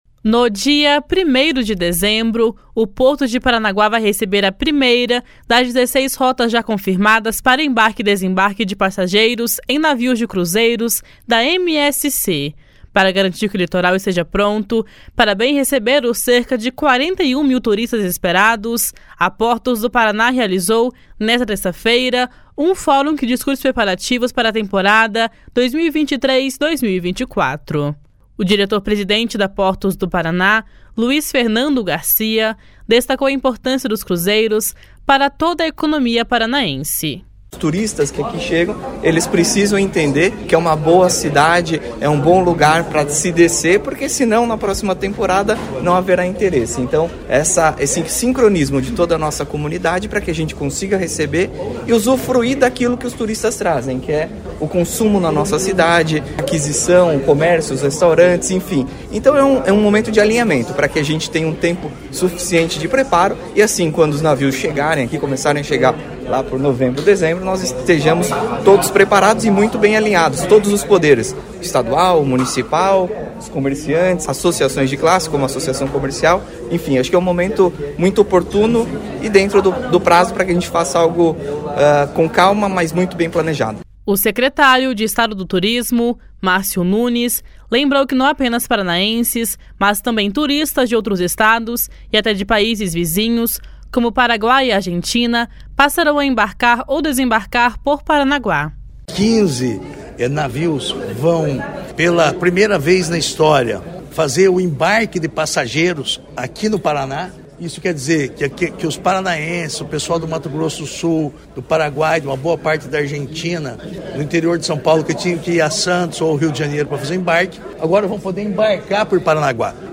O diretor-presidente da Portos do Paraná, Luiz Fernando Garcia, destacou a importância dos cruzeiros para toda a economia paranaense.
O secretário de Estado do Turismo, Márcio Nunes, lembrou que não apenas paranaenses, mas também turistas de outros estados e até de países vizinhos, como Paraguai e Argentina, passarão a embarcar ou desembarcar por Paranaguá.